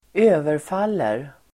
Uttal: [²'ö:verfal:er]